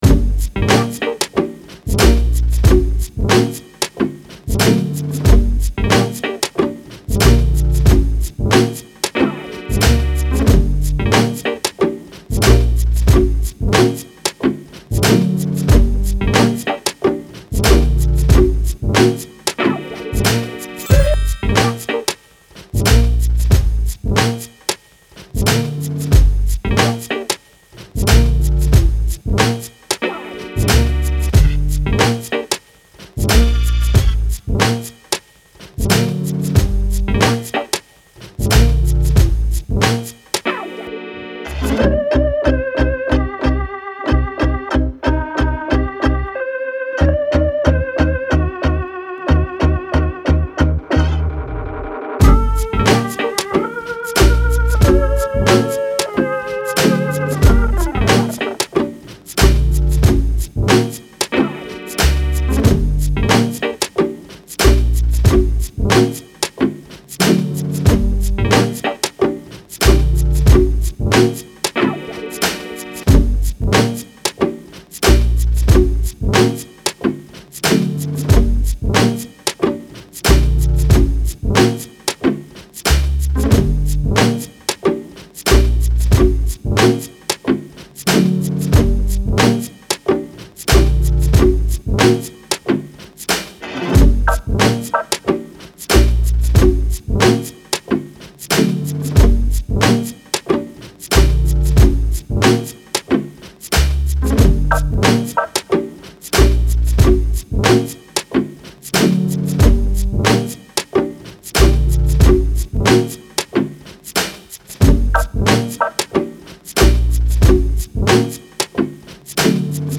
Strange downtempo groovy hip hop beat with peculiar twist.